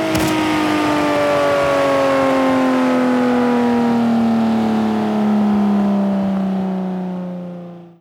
Index of /server/sound/vehicles/lwcars/lam_reventon
slowdown_slow.wav